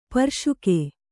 ♪ parśuke